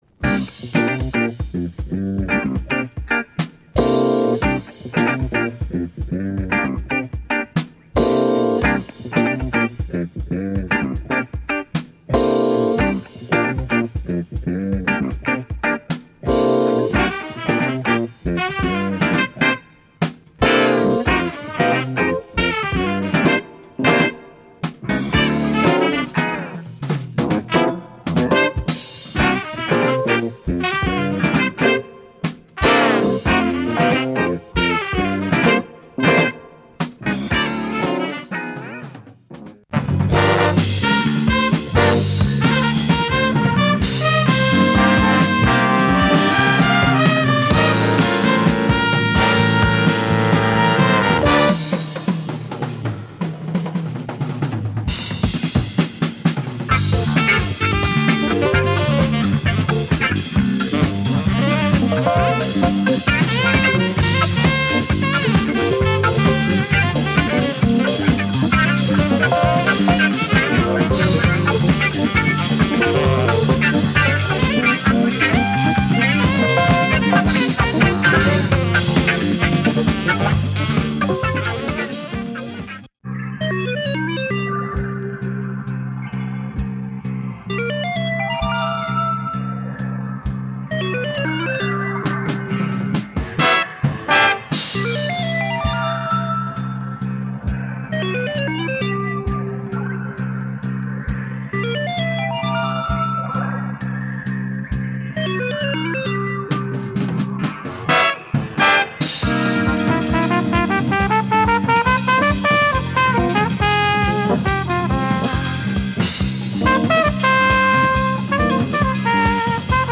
A nice big band jazz funk album coming from Belgium.
has spacy groove beats
are very funky
Lots of Fender Rhodes on the whole LP